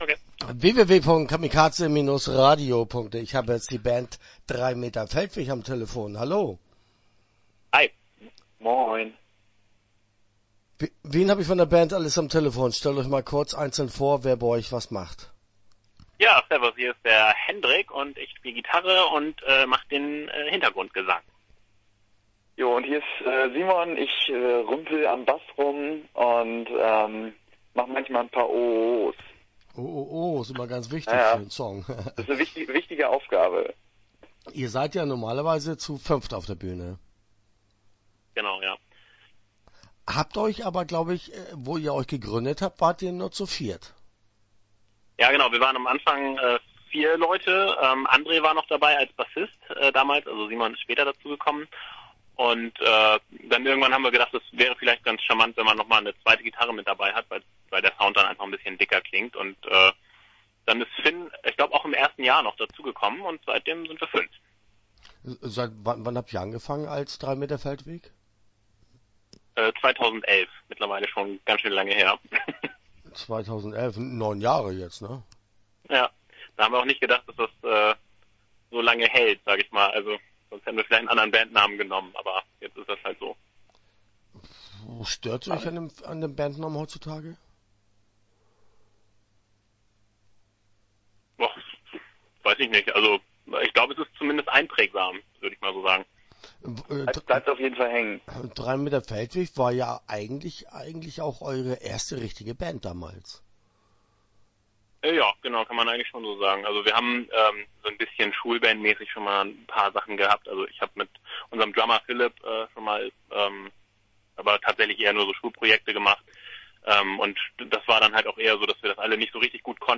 Drei Meter Feldweg - Interview Teil 1 (10:49)